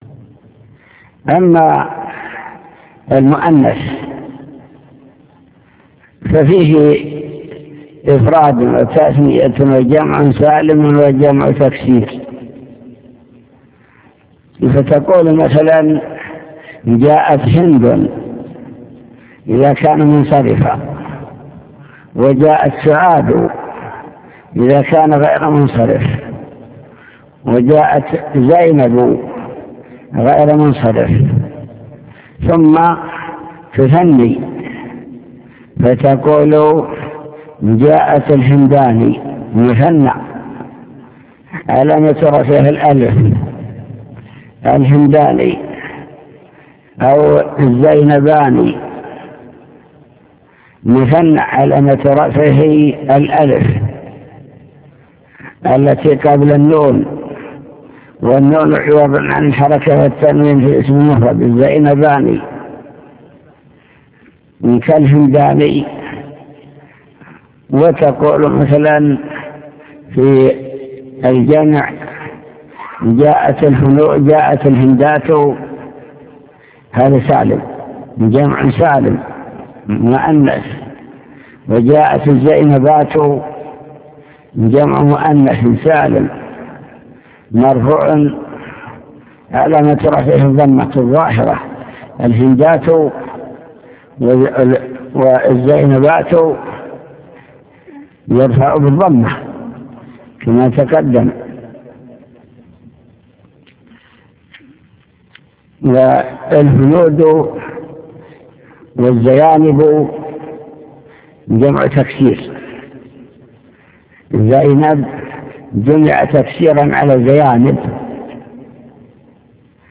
المكتبة الصوتية  تسجيلات - كتب  شرح كتاب الآجرومية باب الفاعل أقسام الفاعل أنواع الفاعل الظاهر